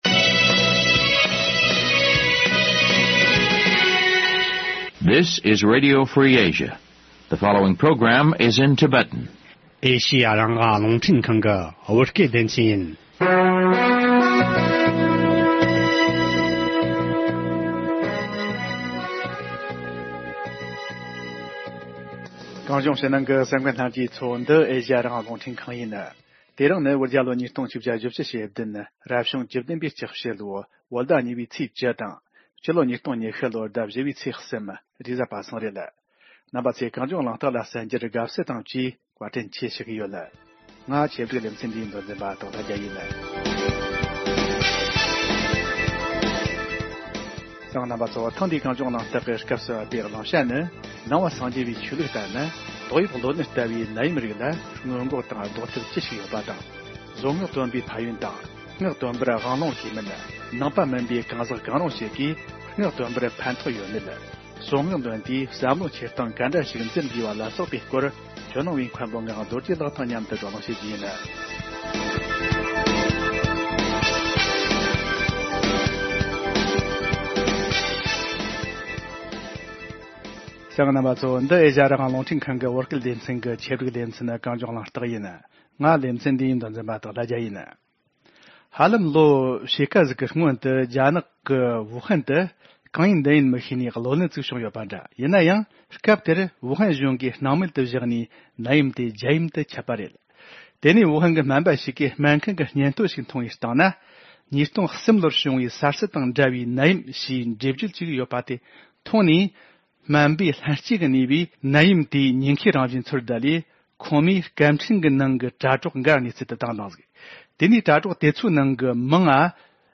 བགྲོ་གླེང་ཞུས་པ་གསན་རོགས་གནང་།